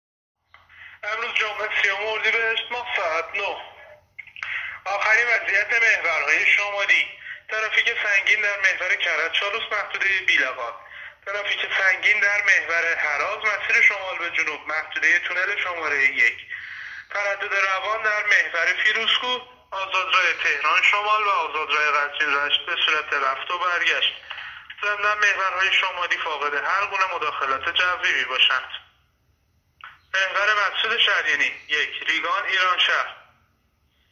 گزارش رادیو اینترنتی از آخرین وضعیت ترافیکی جاده‌ها تا ساعت ۹ سی‌ام اردیبهشت؛